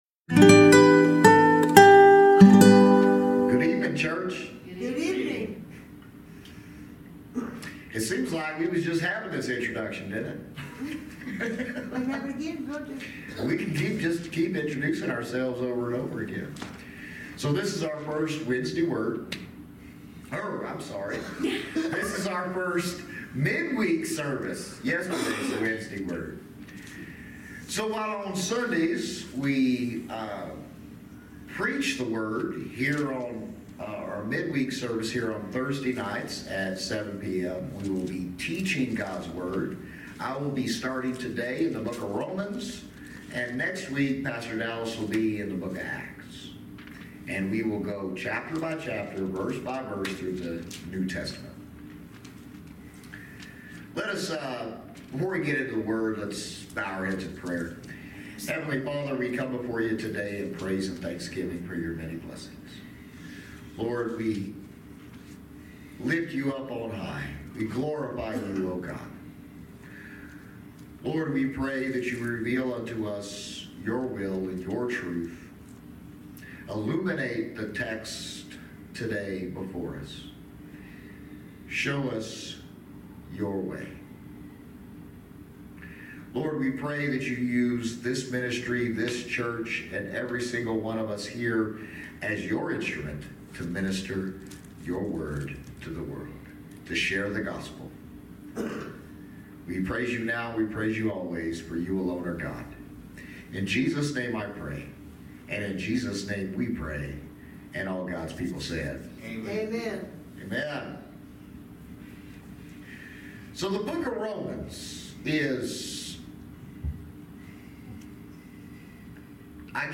Romans Passage: Romans 1:1-17 Service Type: Thirsty Thursday Midweek Teaching